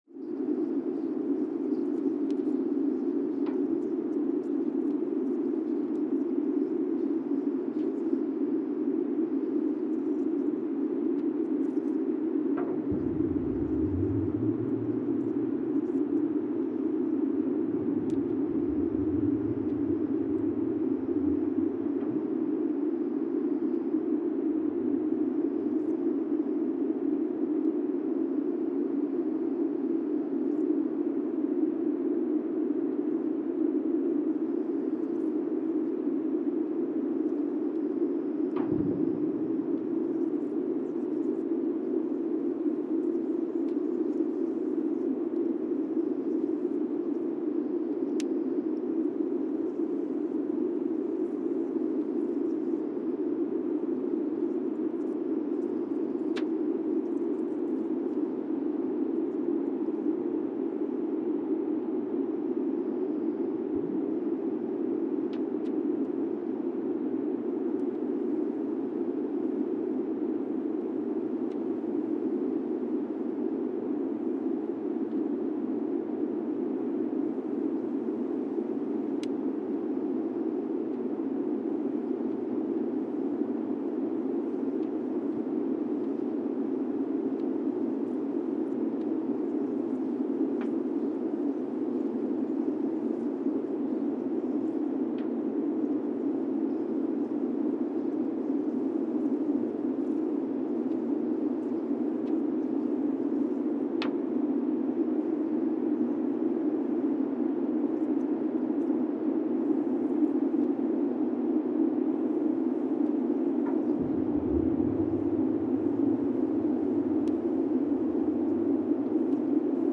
Monasavu, Fiji (seismic) archived on January 13, 2020
Sensor : Teledyne Geotech KS-54000 borehole 3 component system
Speedup : ×1,800 (transposed up about 11 octaves)
Loop duration (audio) : 05:36 (stereo)